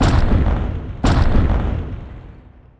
walk_2.wav